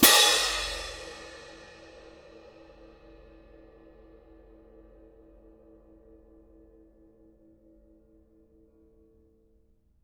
cymbal-crash1_ff_rr1.wav